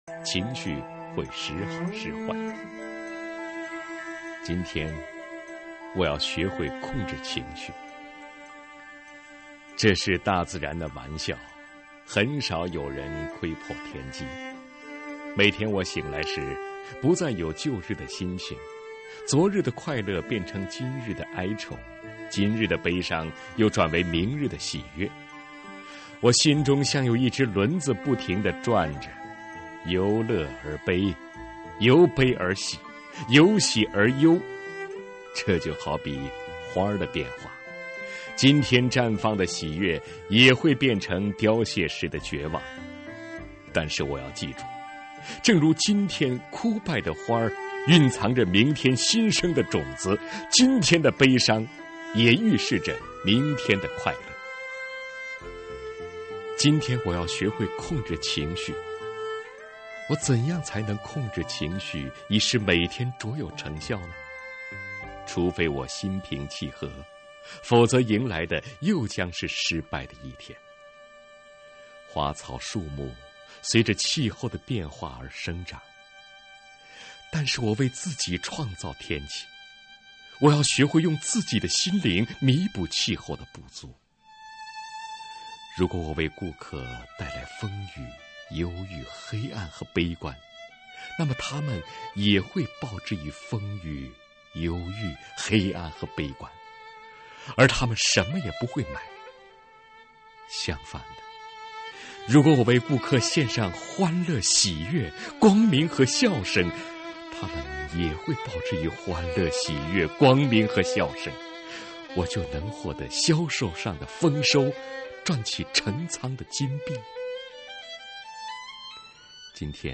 （提琴）